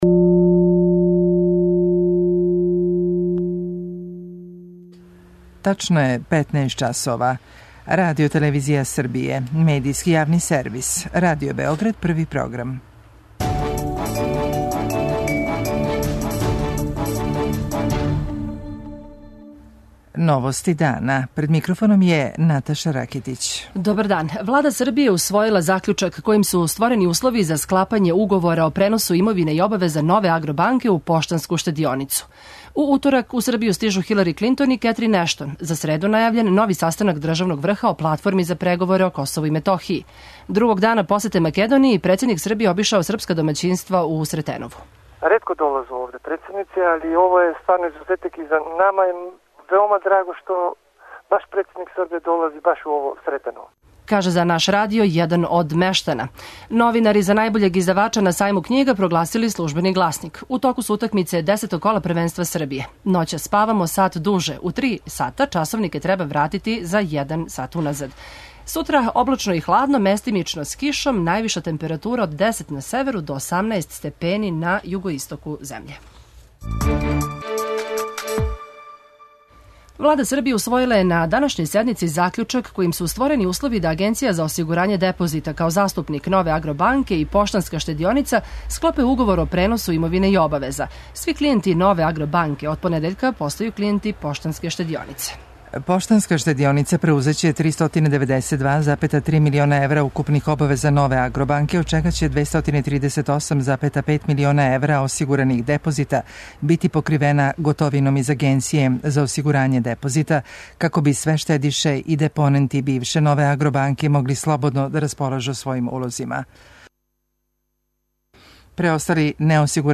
Председник Томислав Николић наставља тродневну посету Македонији током које се сусрео са државним врхом те земље и припремао прославу 100. годишњице почетка балканских ратова заказану за недељу у Куманову. У Новостима дана чућете и једног од мештана села које је посетио председник Николић.